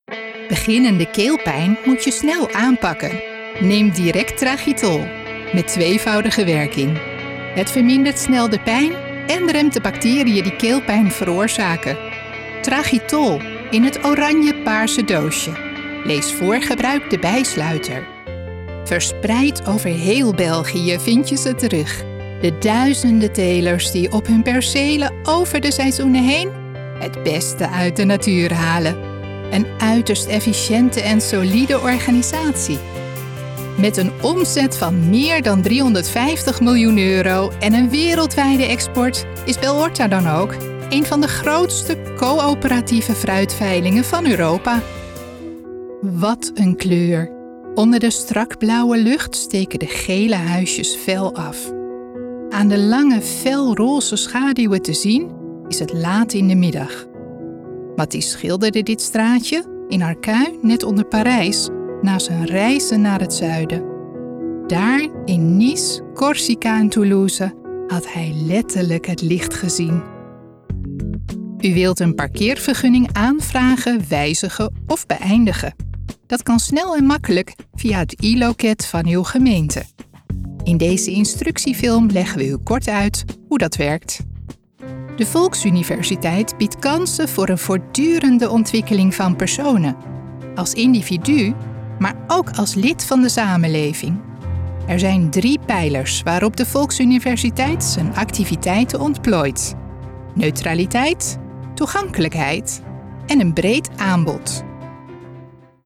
Ben je op zoek naar een vriendelijk, vrolijk en warm stemgeluid?
Naast deze stem ben ik in het bezit van een thuisstudio, een praktijk voor logopedie en een gezonde dosis durf, humor en doorzettingsvermogen.